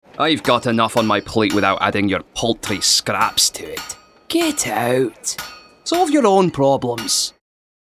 • Male
Scottish Accent. Strong, Confident, Comedic
Scottish.mp3